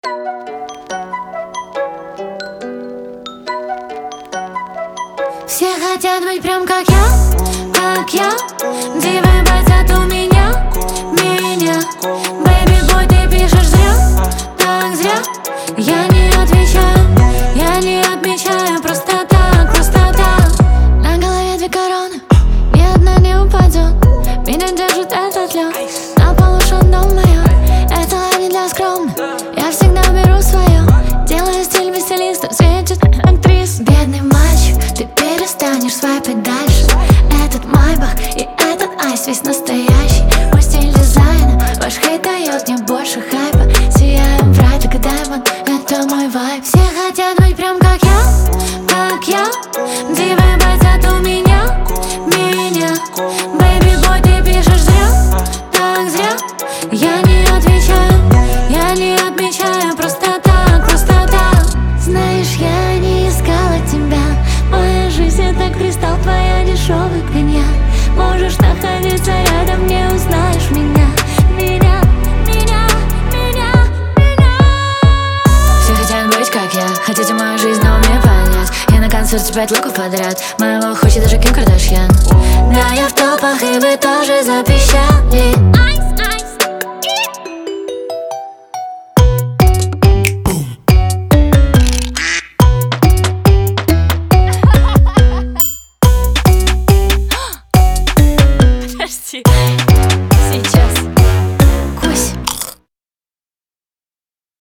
яркий пример современного поп-музыки с элементами R&B